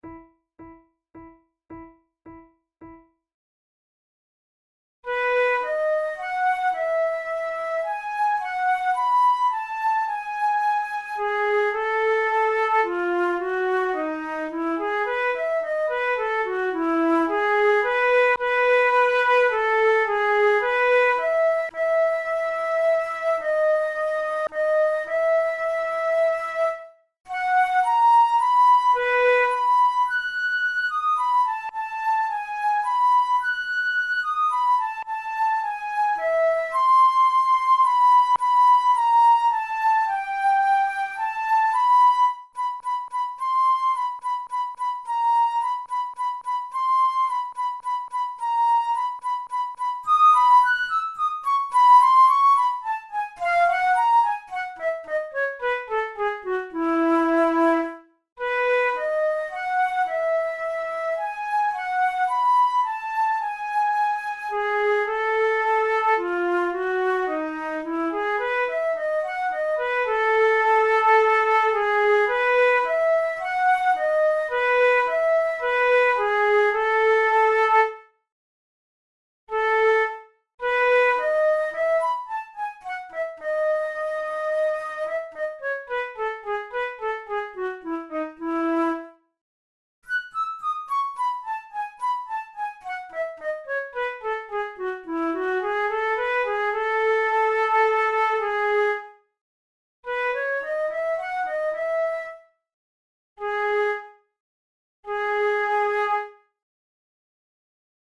This Andante in E major is the central movement of the fourth flute duet from Six duos faciles et brillants by Danish flutist and composer Niels Peter Jensen.
Categories: Romantic Written for Flute Difficulty: intermediate
jensen-op16-no4-ii-andante-1.mp3